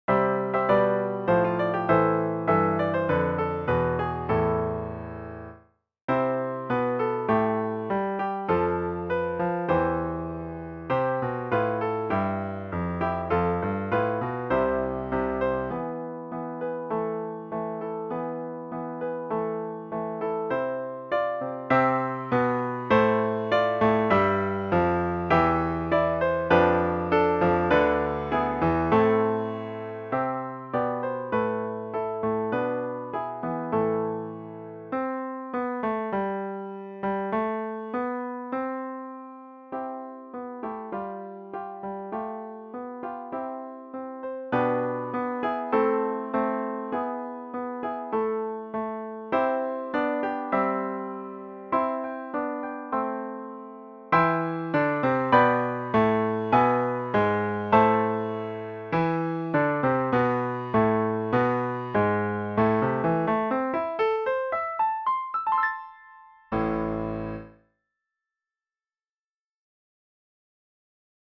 Christmas Piano Book – intermediate to late intermediate